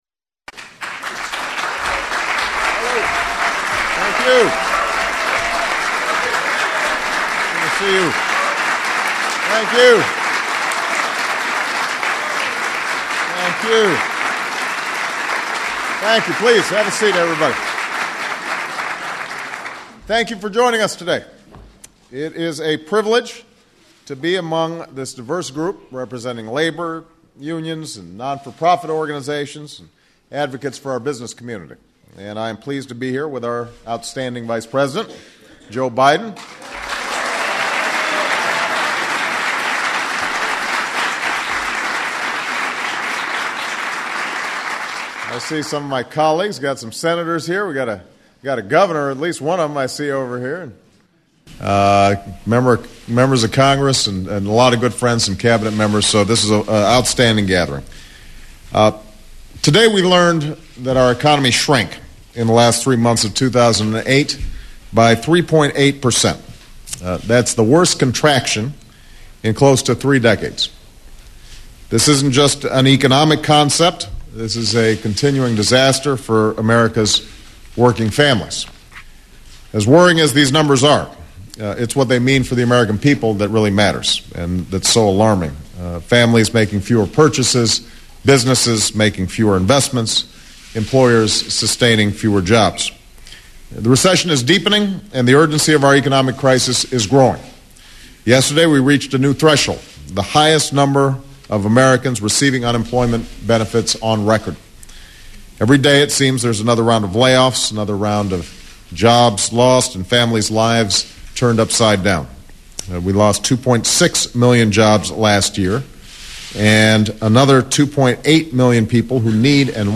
President Barack Obama speaks on the many troubles facing the American middle class.
Job creation Labor unions--Organizing United States Material Type Sound recordings Language English Extent 00:18:54 Venue Note Broadcast on CNN, Jan. 30, 2009.